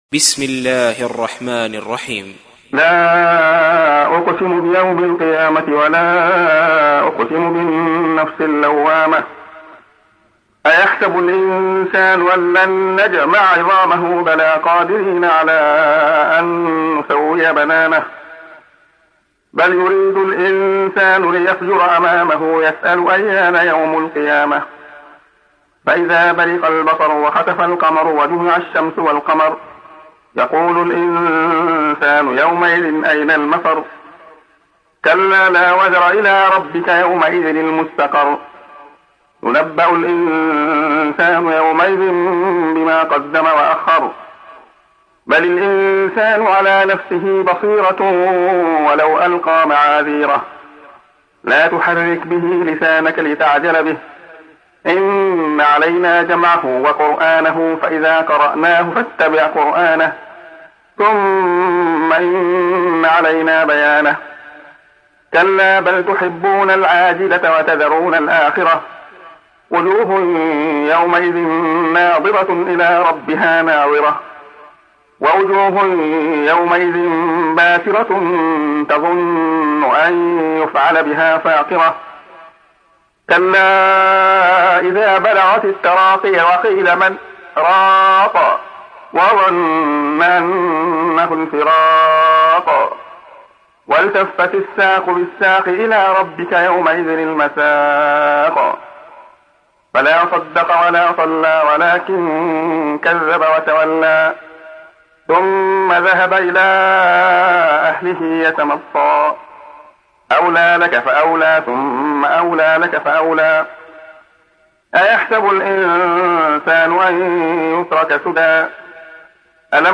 تحميل : 75. سورة القيامة / القارئ عبد الله خياط / القرآن الكريم / موقع يا حسين